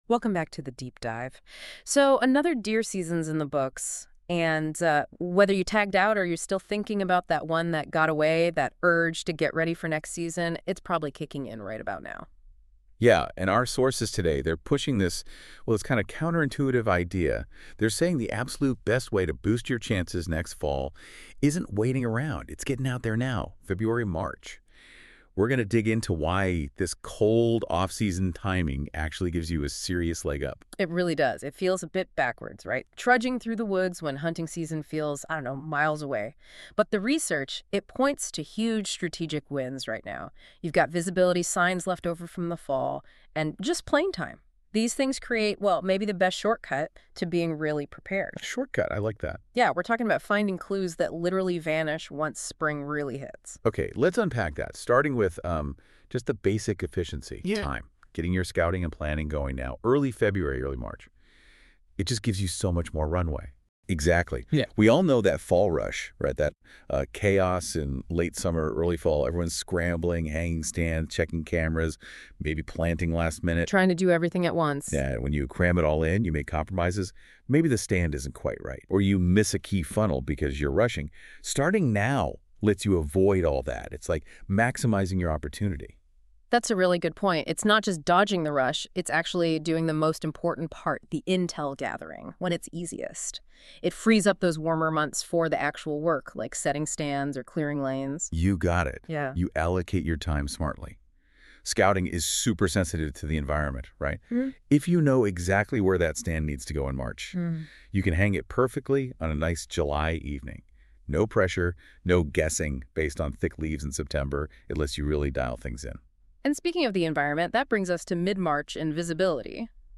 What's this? Audio summary: